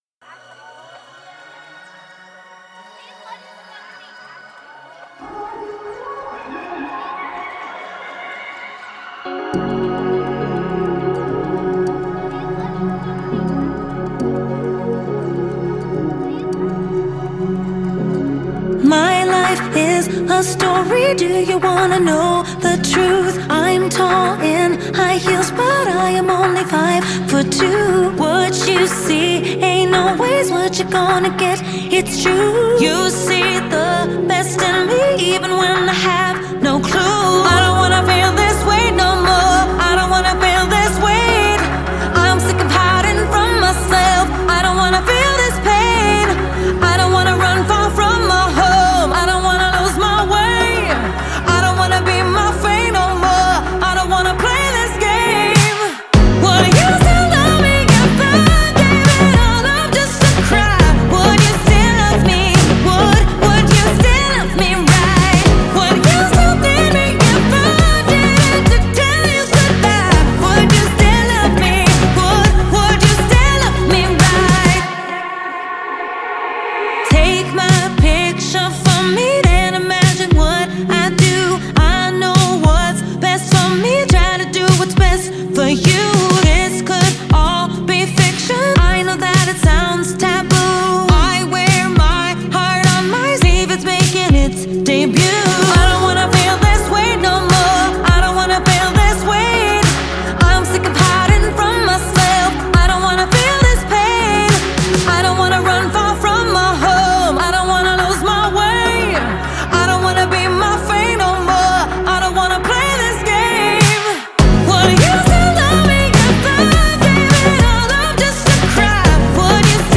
это энергичная поп-песня в стиле dance